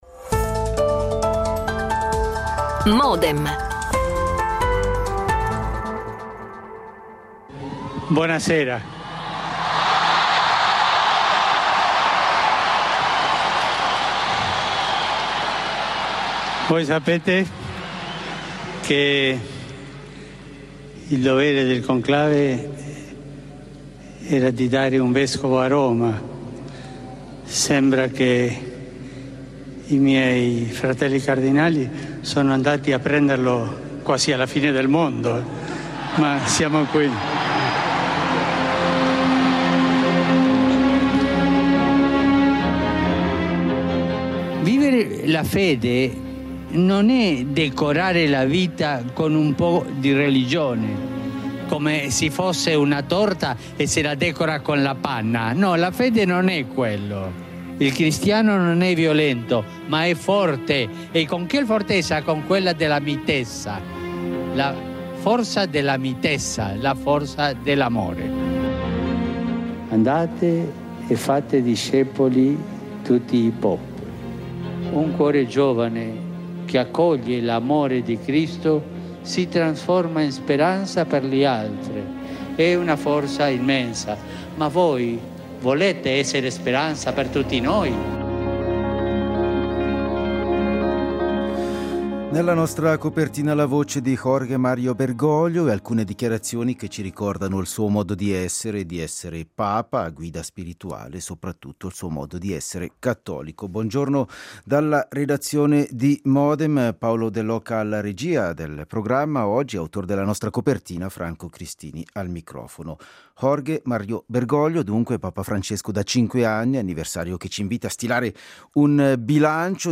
Intervista registrata a: Martin Werlen , abate emerito di Einsiedeln.